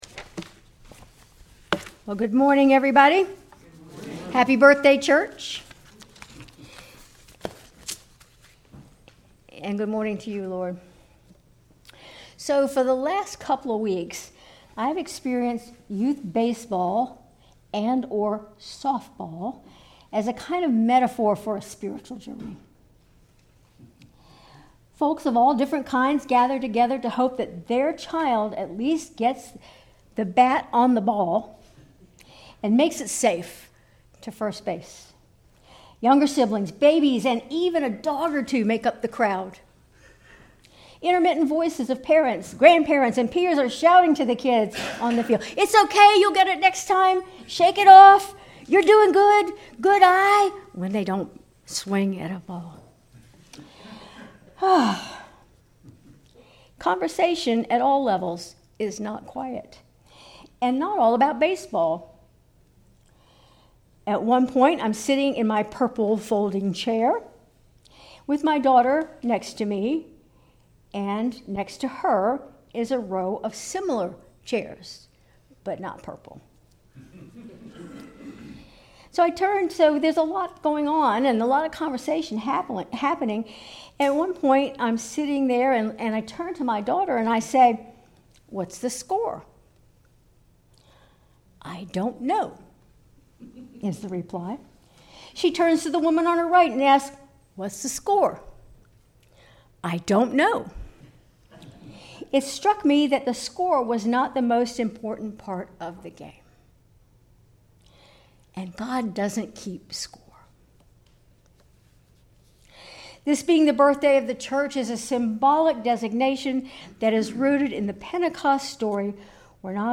Sermon June 8, 2025